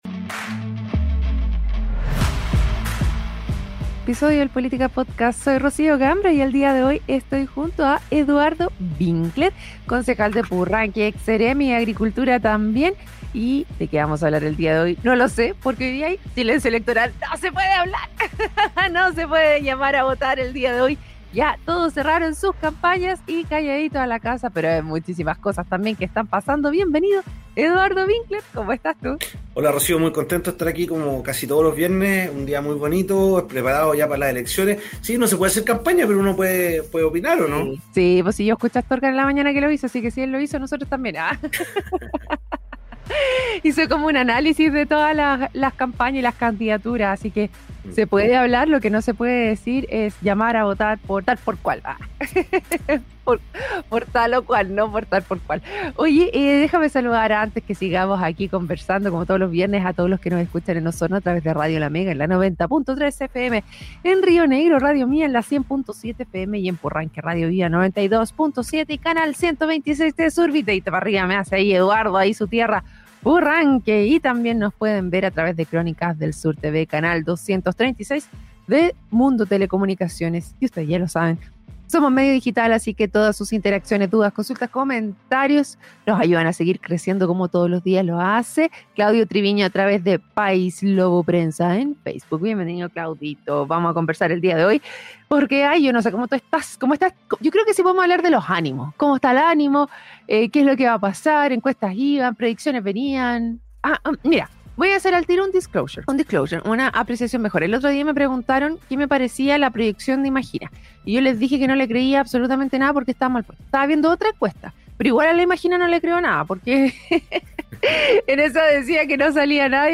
La conversación se centró en la propuesta de indultar a personal de Carabineros , generando un debate sobre las responsabilidades del Estado y la necesidad de una revisión "caso a caso".